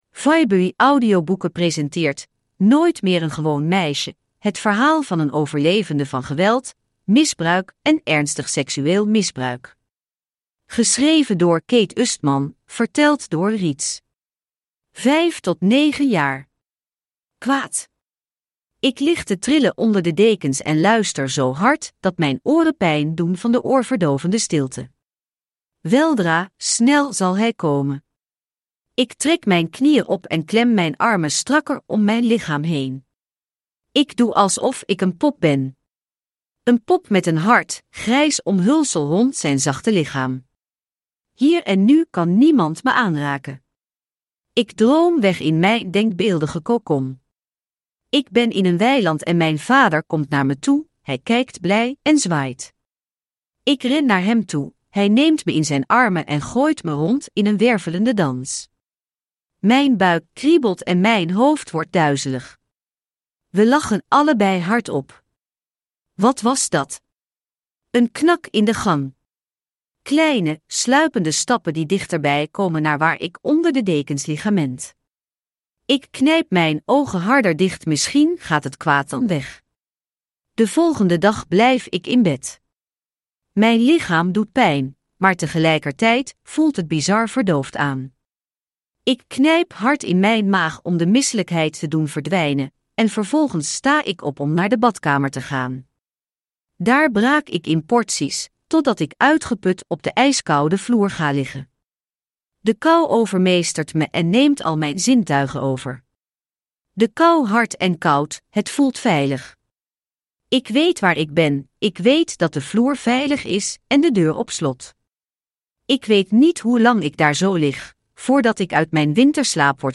Nooit meer een gewoon meisje: het verhaal van een overlever van geweld, misbruik en ernstig, seksueel vergrijp – Ljudbok
Uppläsare: Reedz (AI)